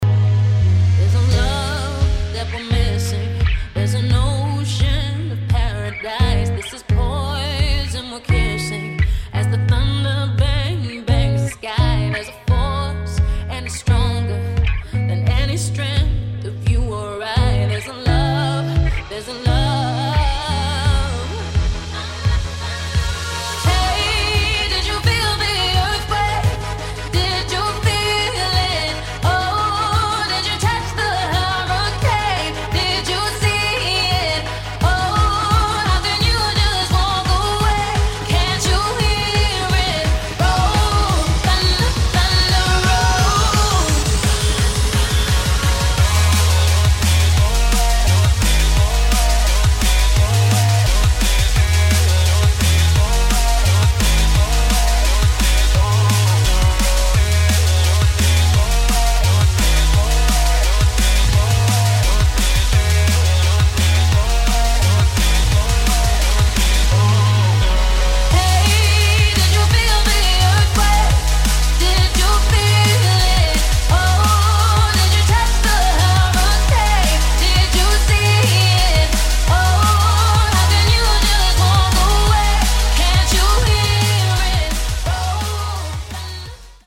[ DRUM'N'BASS / POP / DUBSTEP ]